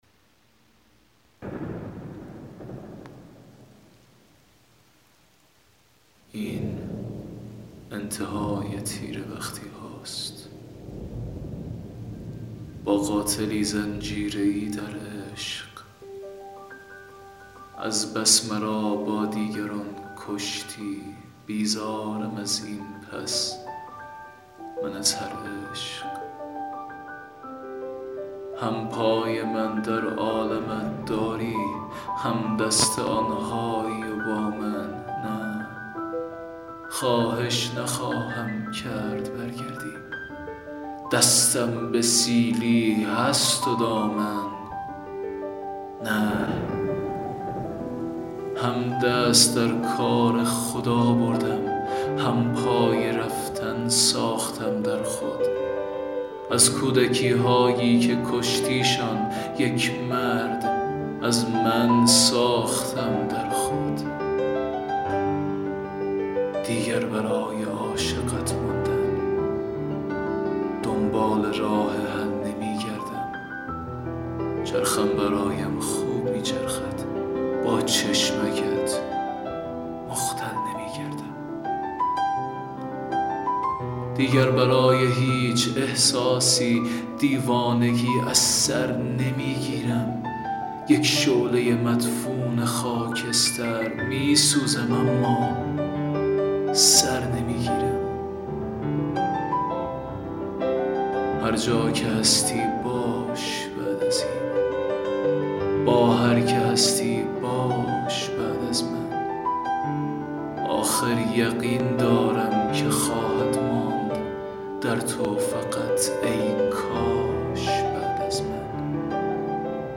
دکلمه شعر